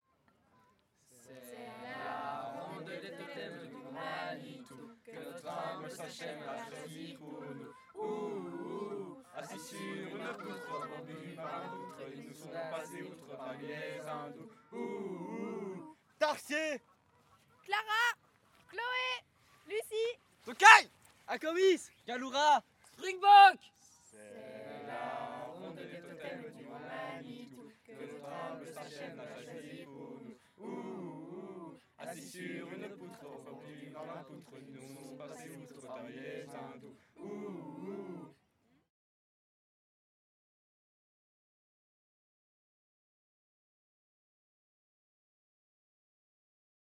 Genre : chant
Type : chant de mouvement de jeunesse
Interprète(s) : Les Scouts marins de Jambes
Lieu d'enregistrement : Jambes